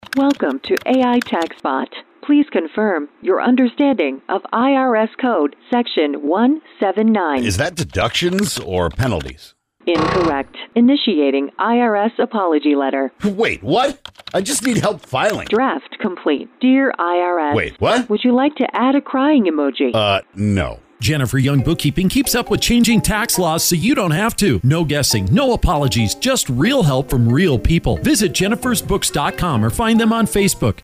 Commercials